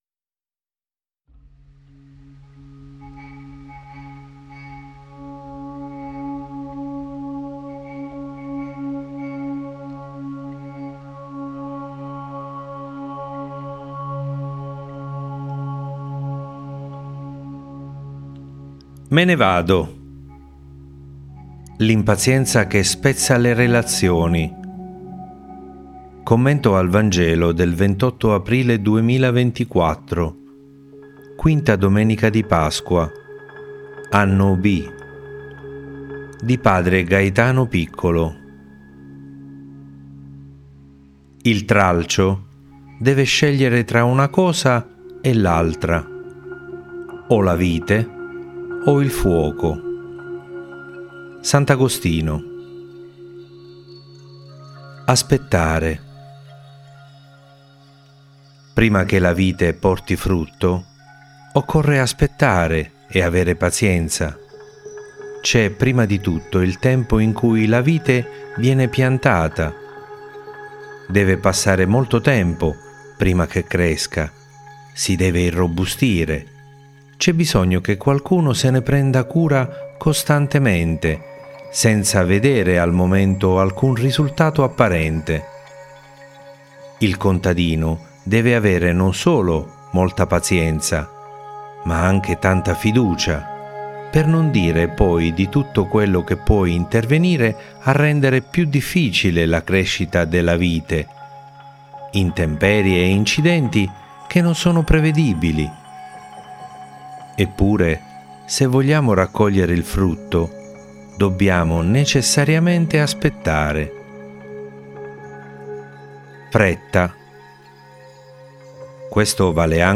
Me ne vado! L’impazienza che spezza le relazioni. Commento al Vangelo del 28 aprile 2024